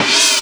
59 CYMB 1 -R.wav